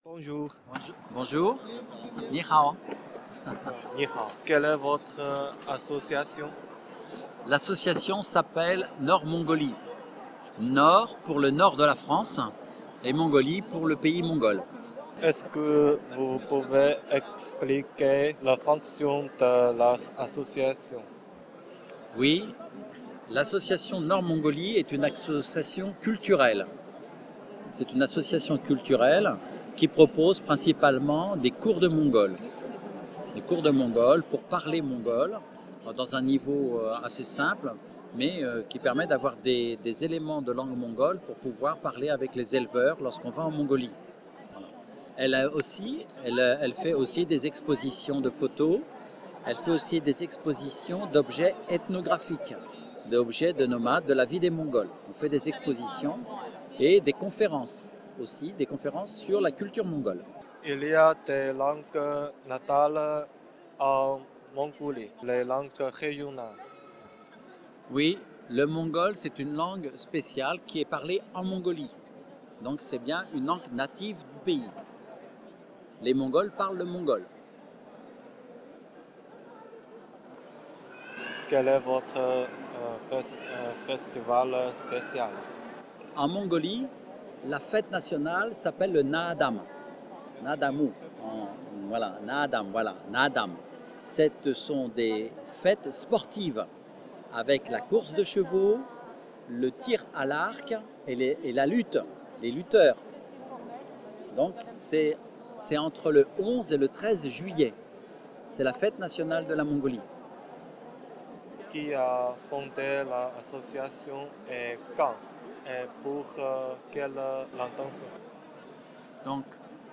Cette 7ème édition du festival des langues a eu lieu les 8 et 9 avril 2011 à la Chambre de Commerce et d'Industrie Grand Lille
L'équipe était constituée d'étudiants chinois de Lille 1